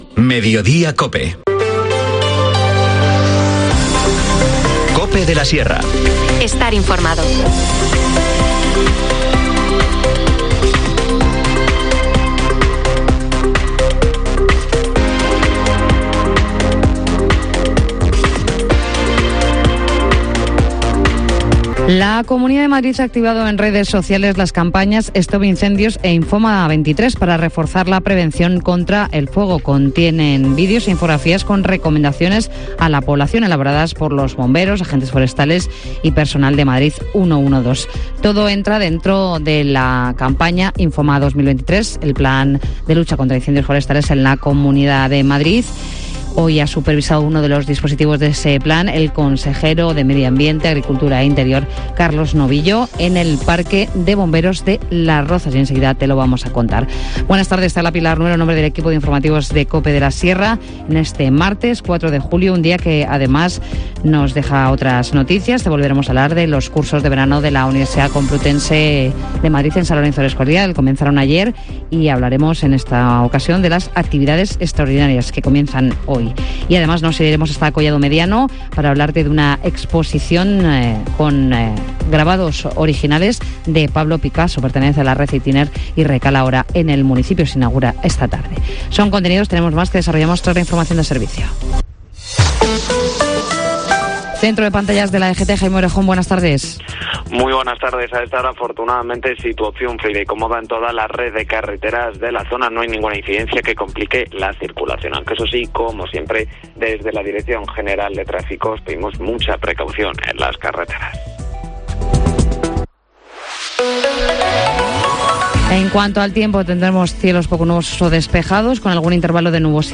Informativo Mediodía 4 julio
INFORMACIÓN LOCAL